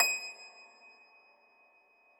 53k-pno23-D5.aif